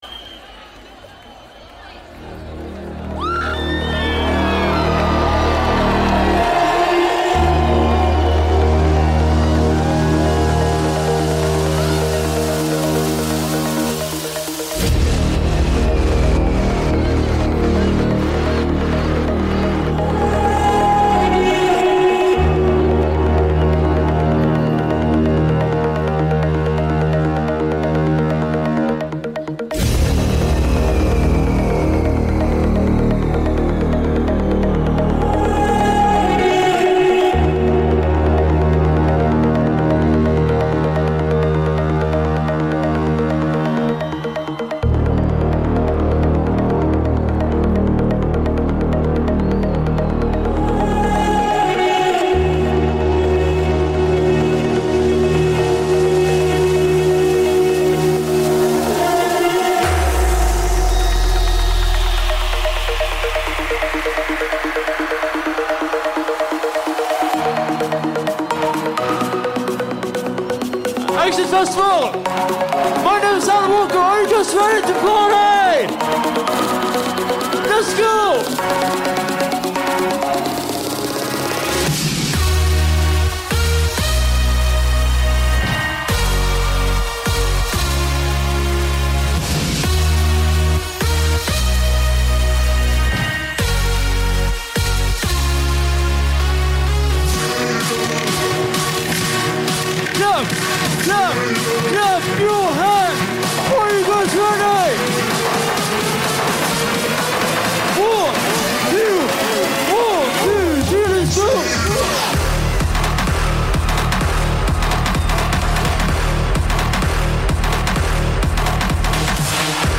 Also find other EDM Livesets, DJ Mixes
Liveset/DJ mix